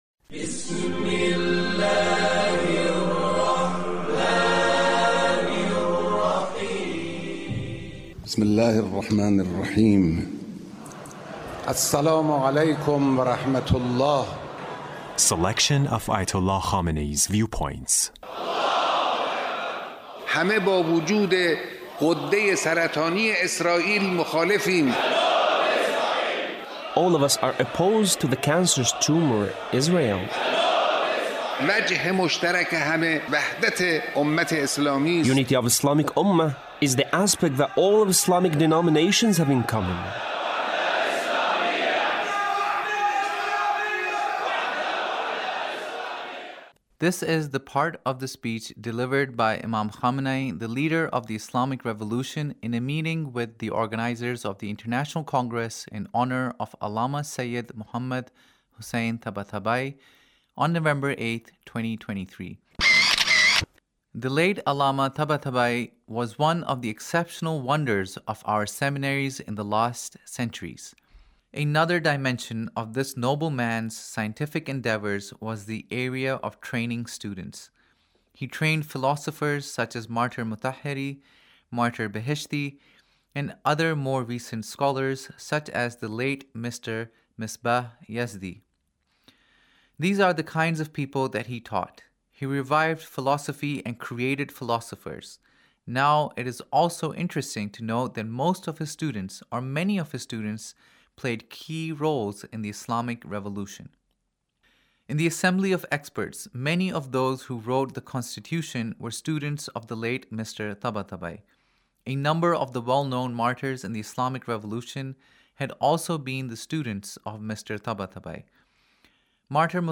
Leader's Speech (1866)